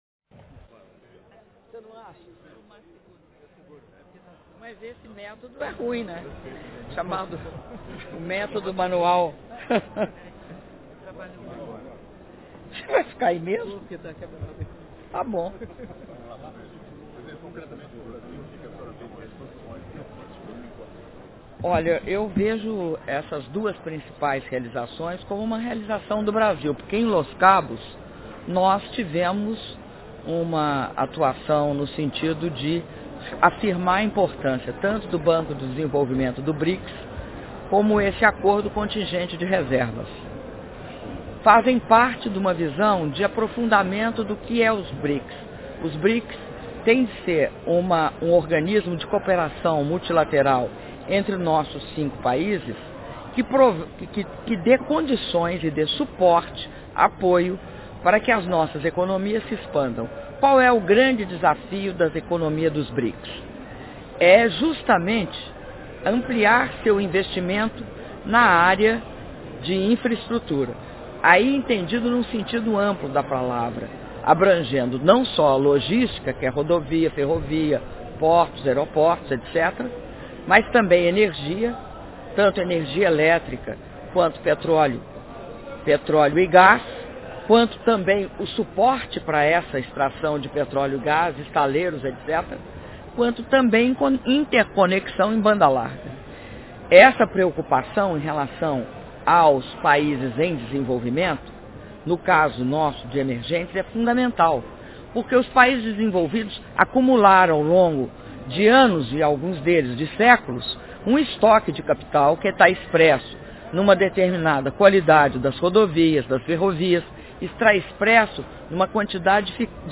Entrevista coletiva concedida pela Presidenta da República, Dilma Rousseff, após declaração à imprensa - Durban/África do Sul